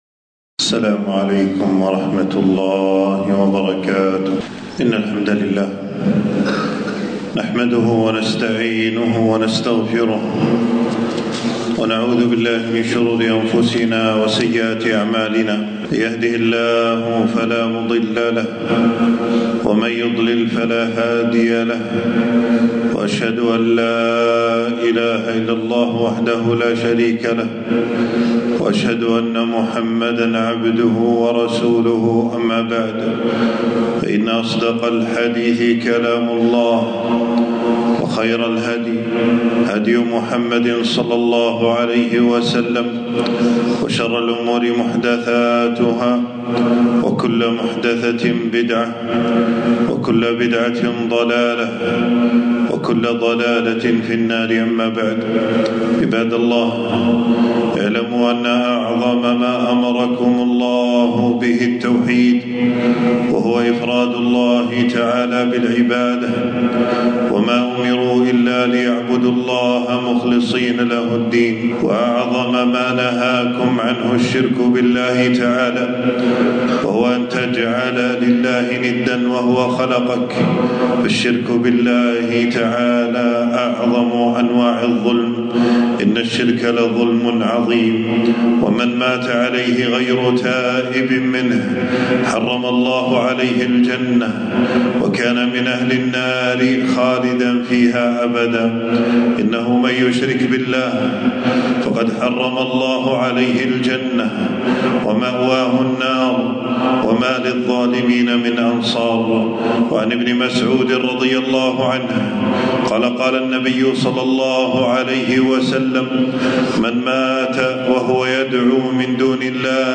تنزيل تنزيل التفريغ خطبة بعنوان: الشرك خطورته وبعض مظاهره .
خطبة في يوم 13 ربيع الأول 1447هـ في مسجد السعيدي بالجهرا.